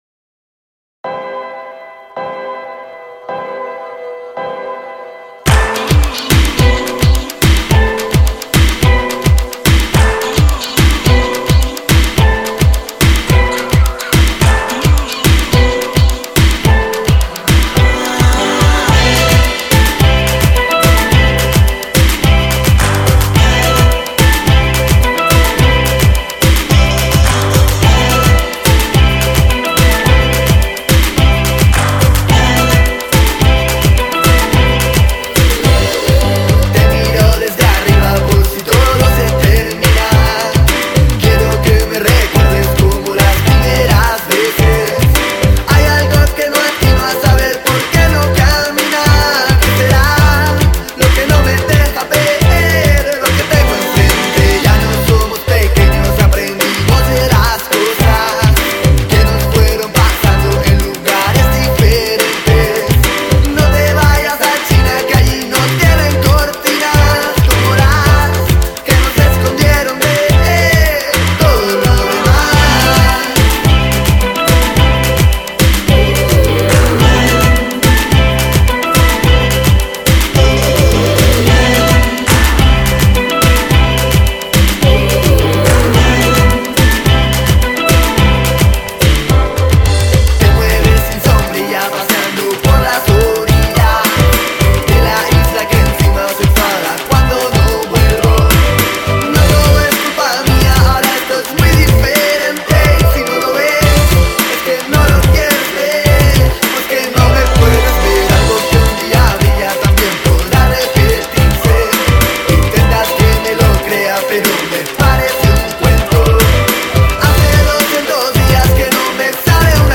An absolutely perfect pop song.